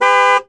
1 channel
Transcription: BEEP